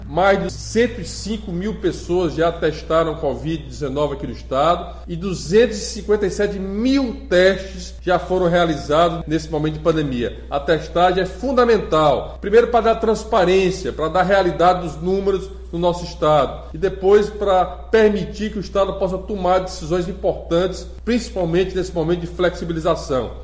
O Estado está entre os que mais testam no País, como comentou o governador Camilo Santana.